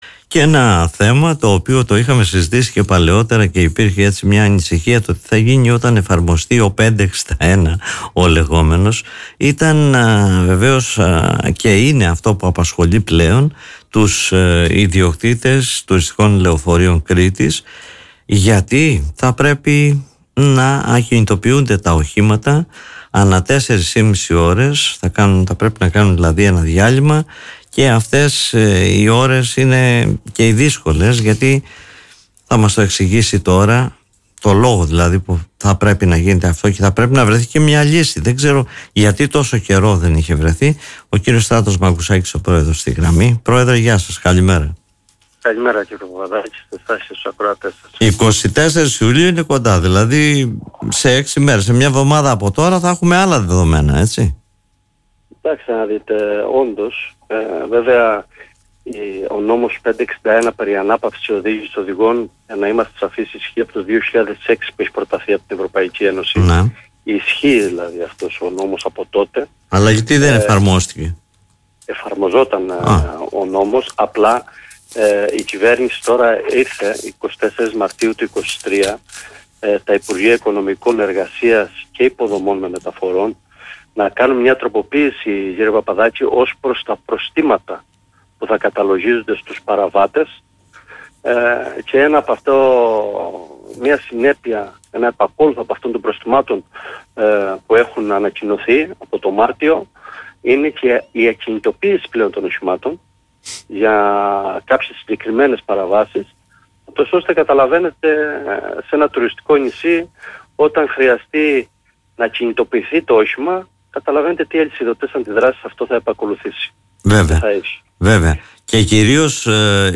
μίλησε στην εκπομπή “Δημοσίως”